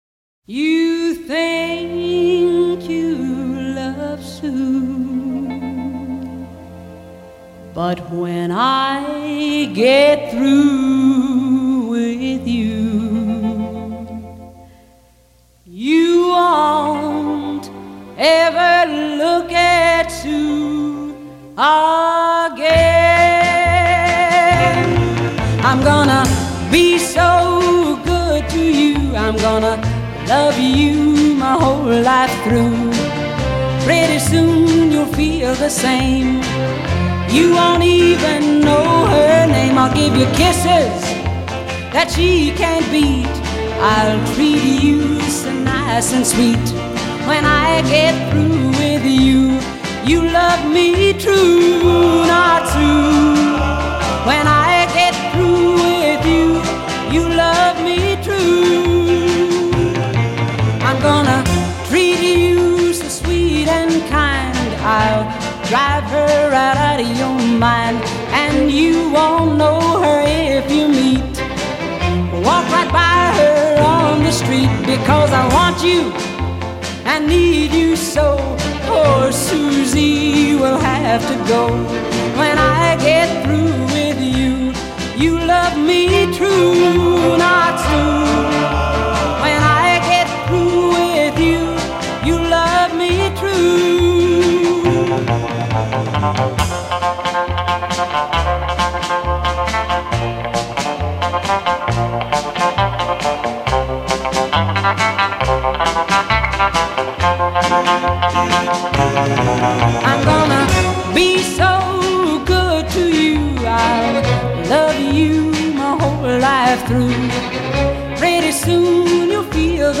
прекрасной певицей
и её весьма приятными и мелодичными композициями.